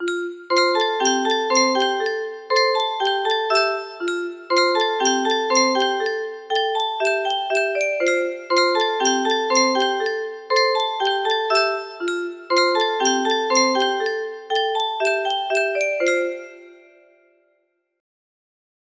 Grand Illusions 30 music boxes More